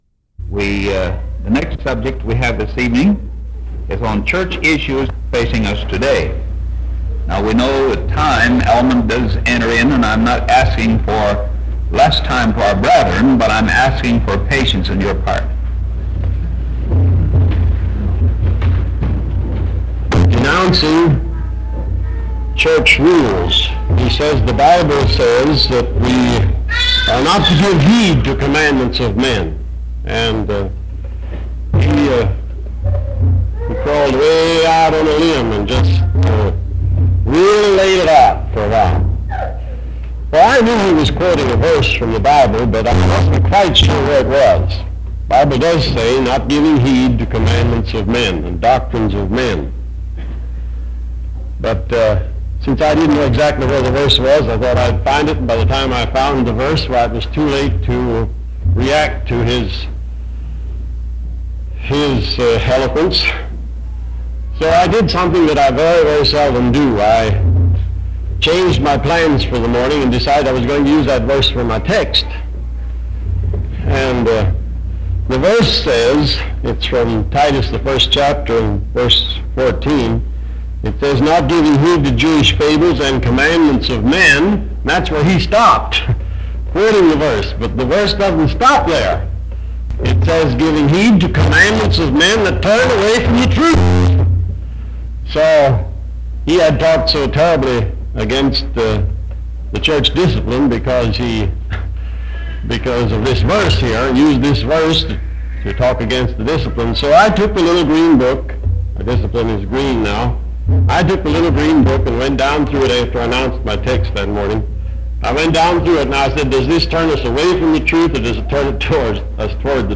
This message was salvaged from long ago, and is being presented as is. It shows the fire and vigor of brethren who were seriously concerned about trends in the church. It is cut short and ends abruptly.